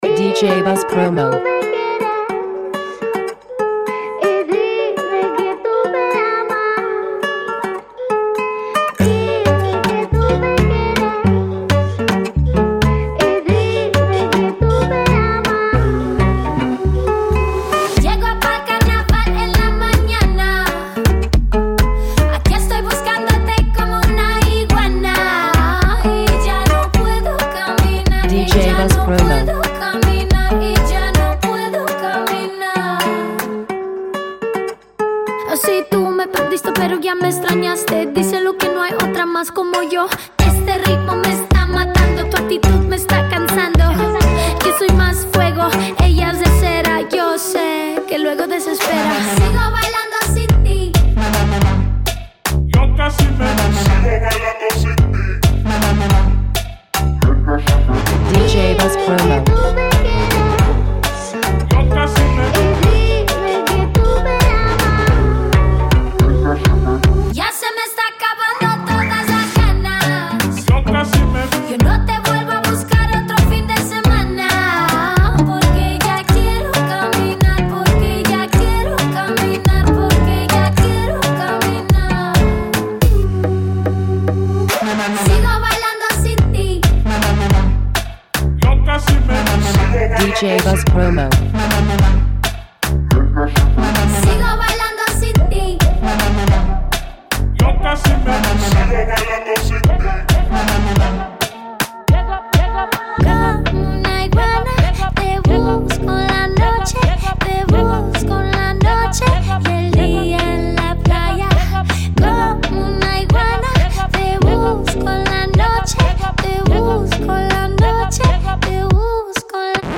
a Spanish song
Original Mix